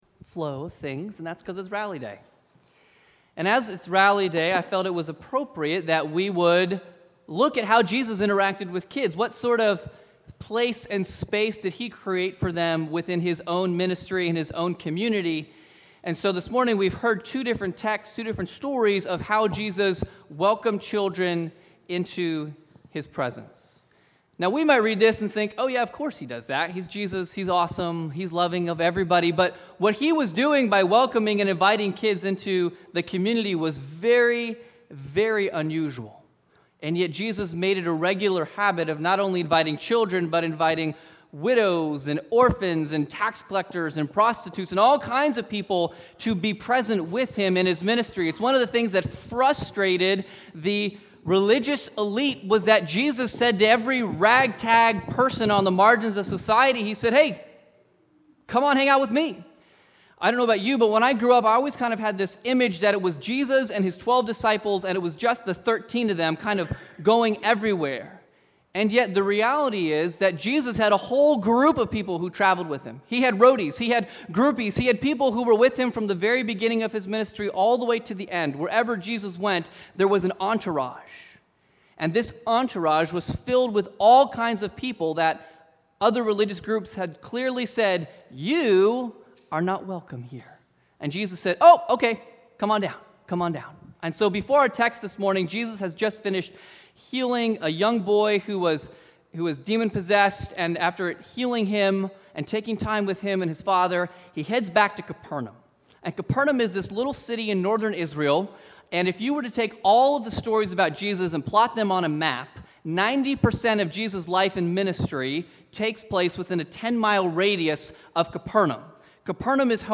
Sermon 9/8/2019 Rally Day “Let the Children Come”
Sermon-9_8_19.mp3